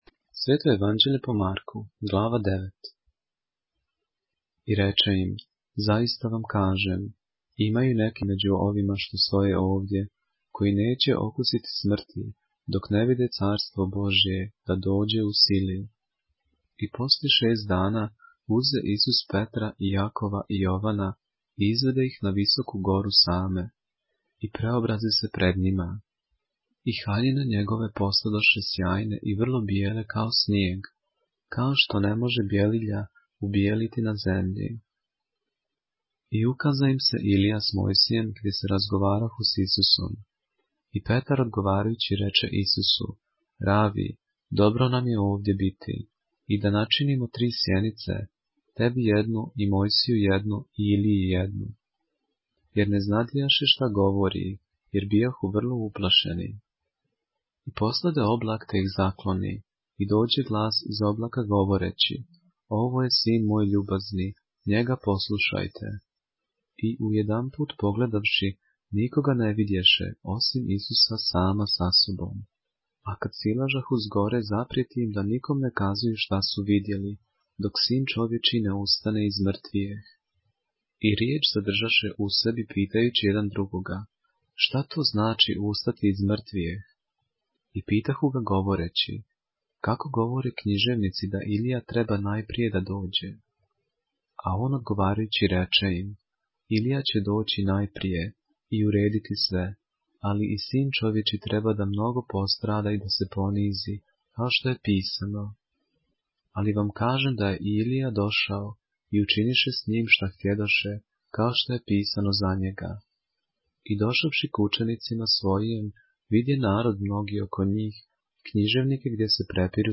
поглавље српске Библије - са аудио нарације - Mark, chapter 9 of the Holy Bible in the Serbian language